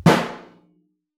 timpsnaretenor_fff.wav